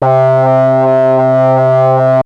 OSCAR OBOE 3.wav